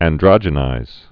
(ăn-drŏjə-nīz)